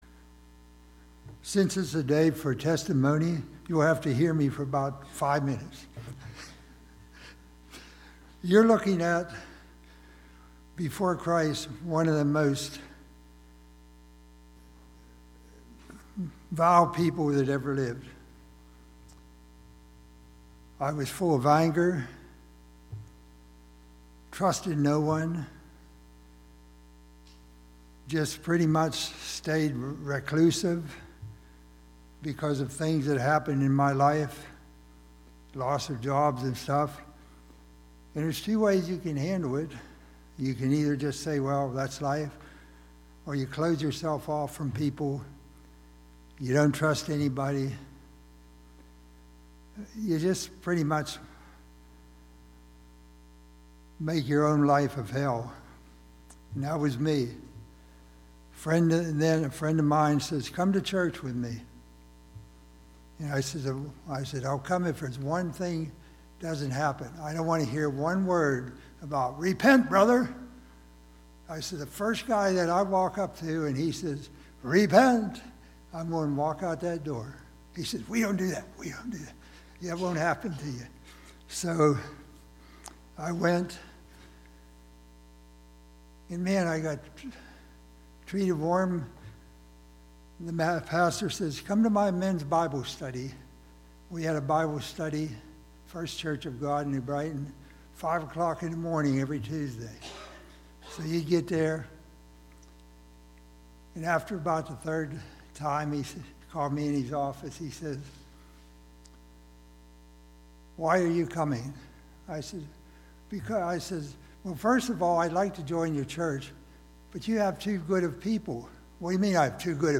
This is OUR Story 11:00am Sanctuary